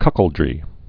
(kŭkəl-drē, kk-)